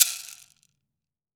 WOOD SHAKER2.WAV